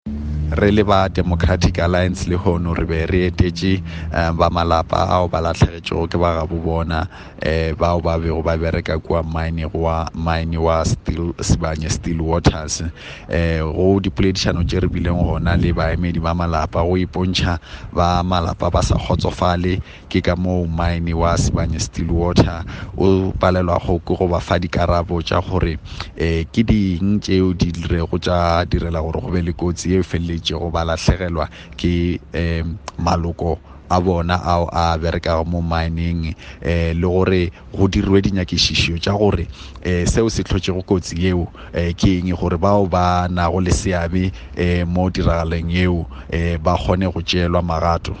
Issued by Solly Malatsi MP – DA National Spokesperson
Sepedi soundbite.
Solly_Malatsi_Sepedi.mp3